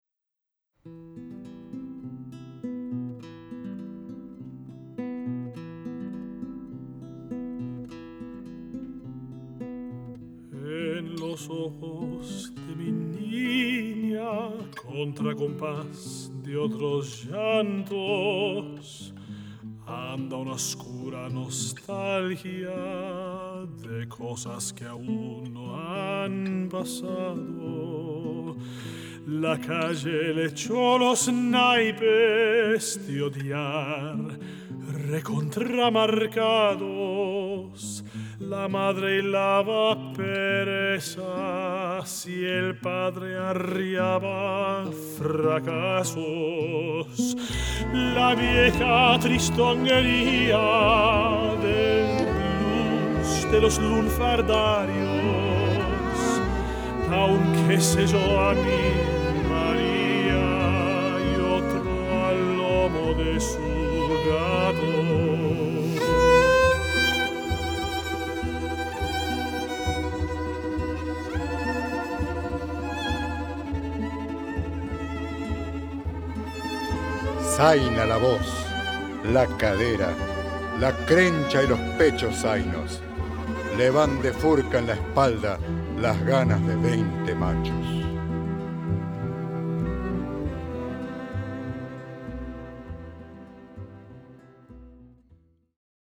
violin
viola
cello
double bass
flute/piccolo
guitar
piano
percussion